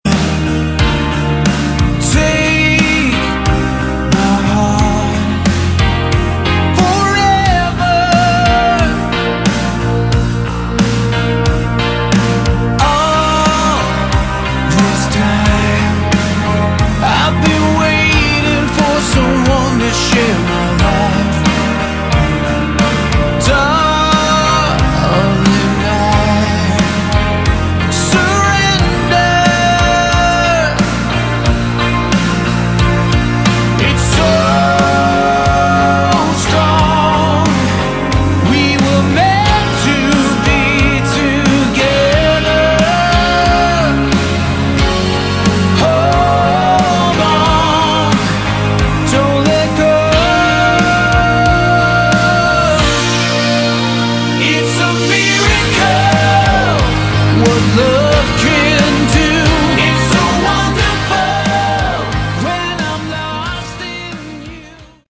Category: AOR / Melodic Rock
bass
vocals, guitar
drums